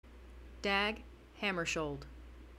When Dag arrived in New York on April 9, 1953, he told the American press that it was fine to pronounce it as “Hammer-shuld” or “Hammer-shield.”
(Recitation by the author.)
Dag-Name-American.mp3